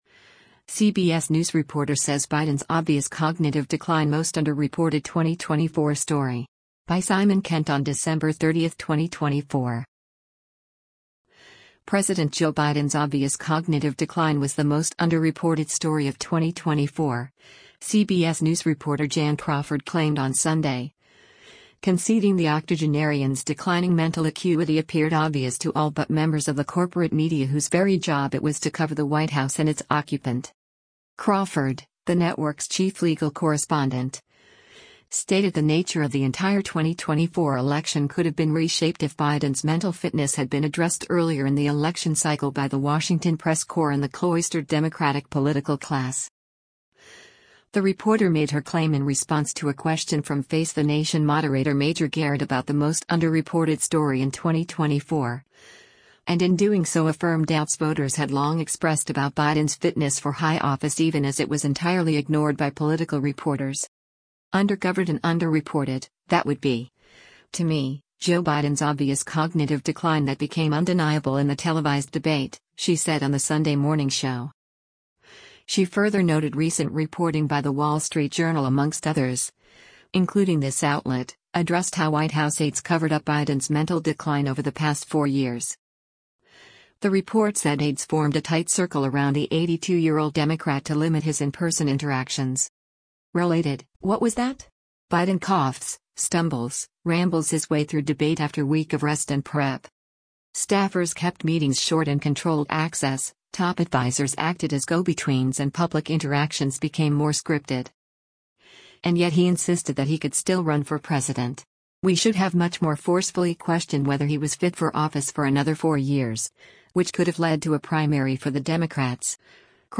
“And yet he insisted that he could still run for president. We should have much more forcefully questioned whether he was fit for office for another four years, which could have led to a primary for the Democrats,” Crawford said during the year-end correspondents roundtable on CBS’ Face the Nation.